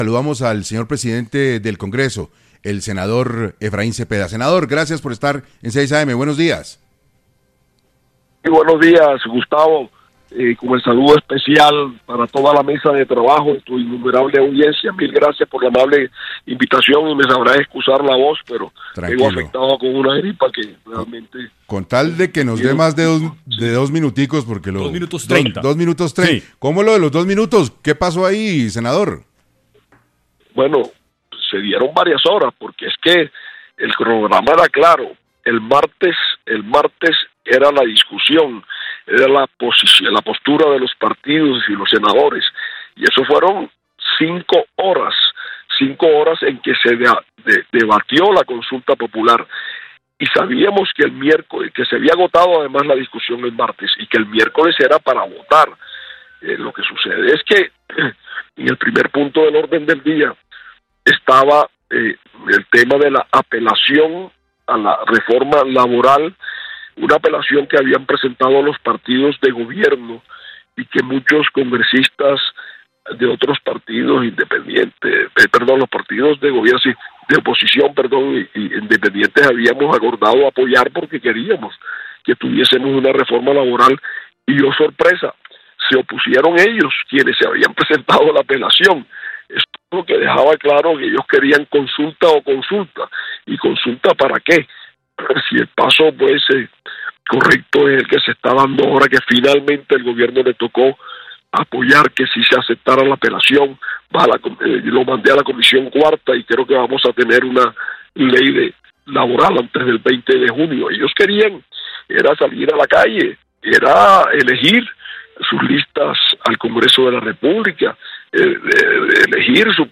En entrevista para 6AM, el presidente del Congreso, Efraín Cepeda, aclaró la situación.